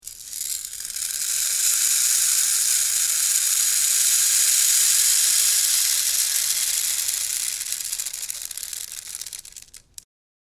Bastoncino della pioggia
Basta abbassare leggermente l’asta e si ode il picchiettio
delicato della pioggia.
• Materiale: metallo, plastica